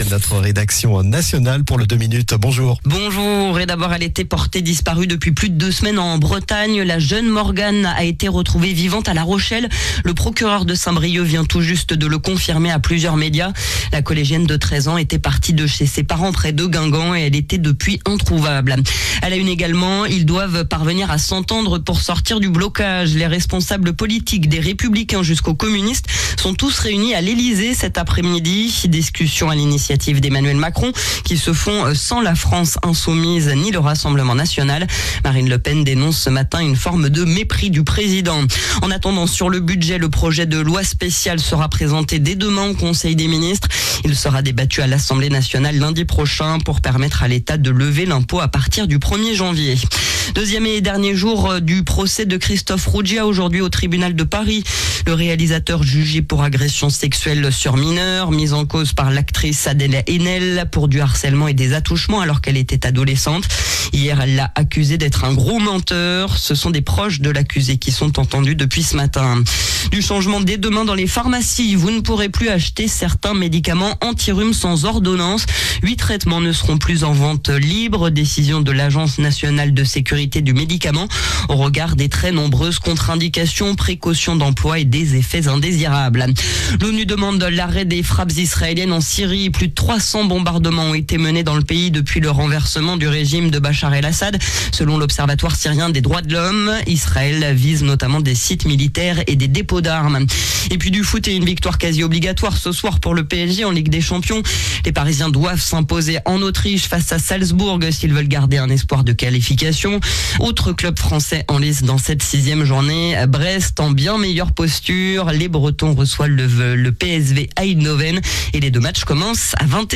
Journal du mardi 10 décembre (midi)